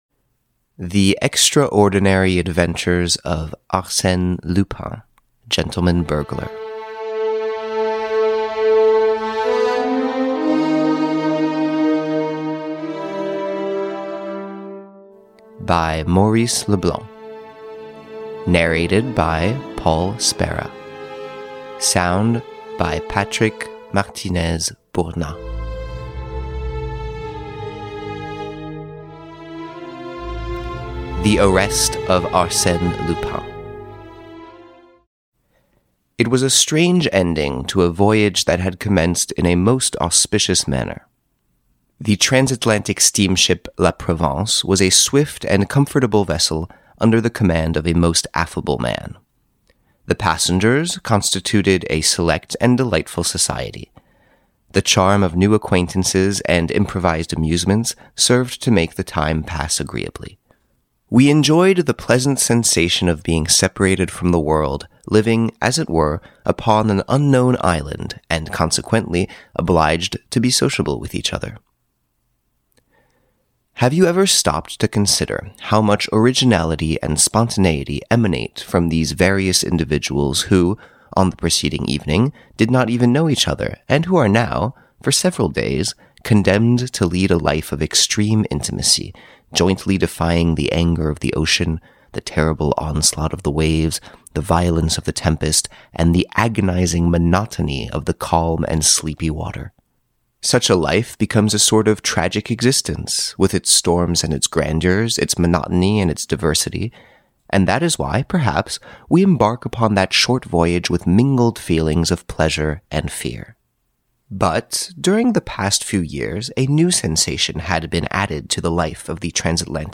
The Arrest of Arsene Lupin, the Adventures of Arsene Lupin the Gentleman Burglar (EN) audiokniha
Ukázka z knihy